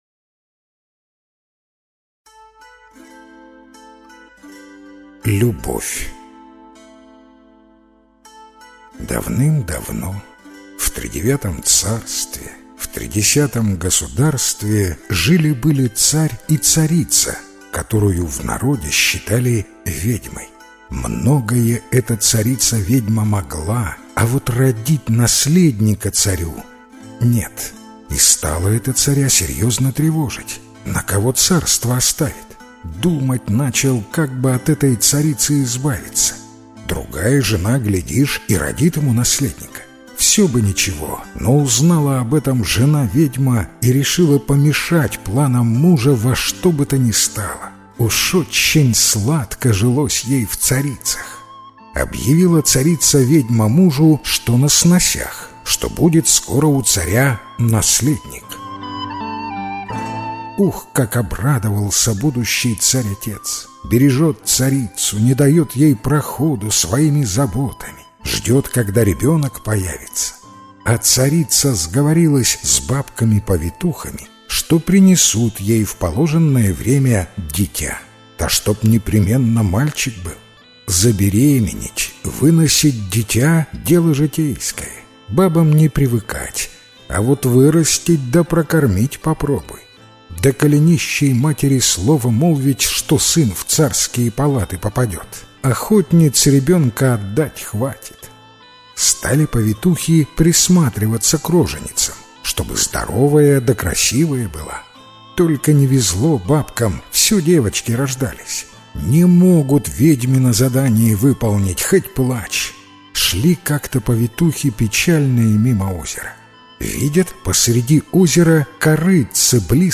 Любовь - белорусская аудиосказка - слушать онлайн